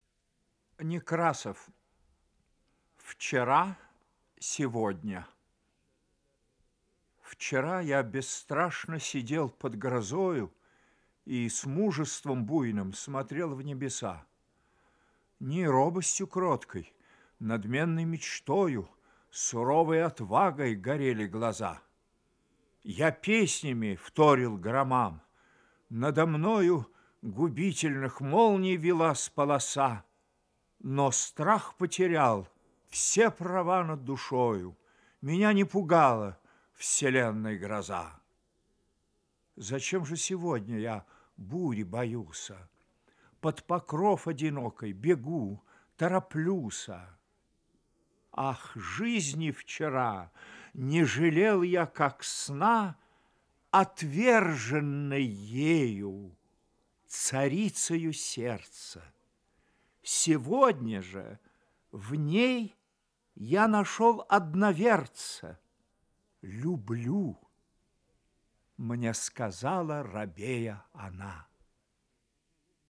2. «Николай Некрасов – Вчера, сегодня (читает Михаил Царёв)» /